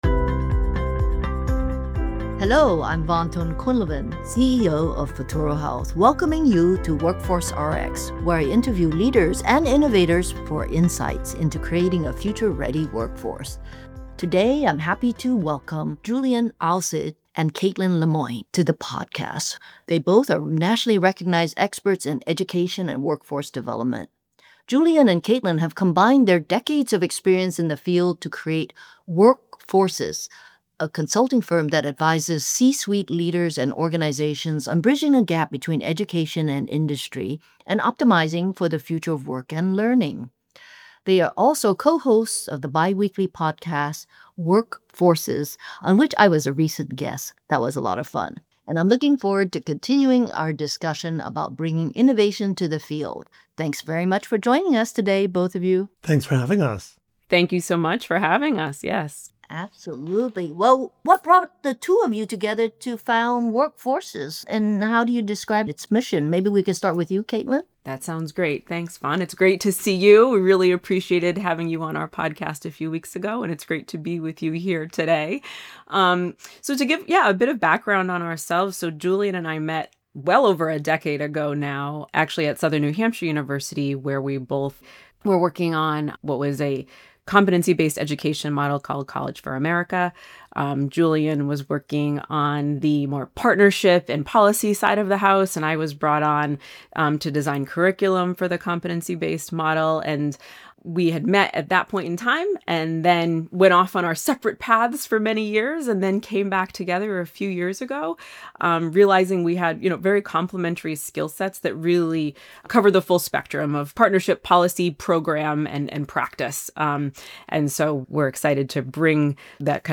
interviews leaders and innovators for insights into the future of work, future of care, future of higher education, and alternative education-to-work models.